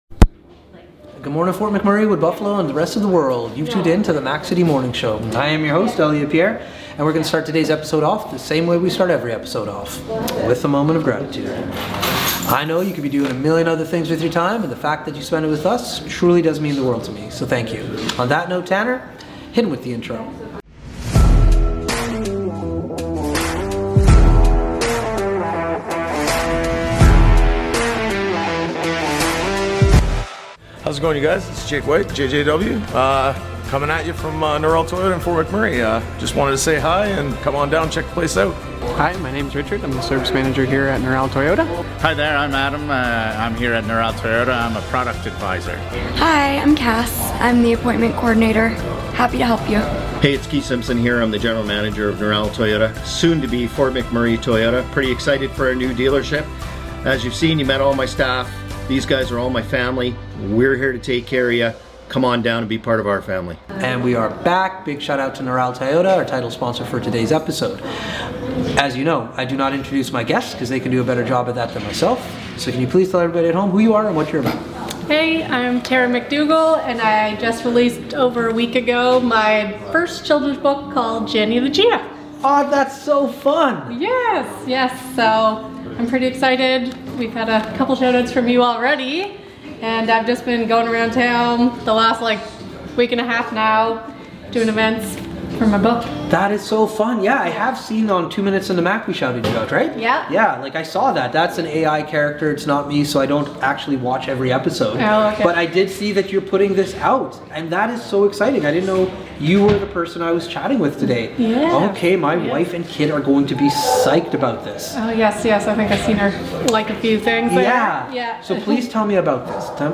The show is back on location at Corridor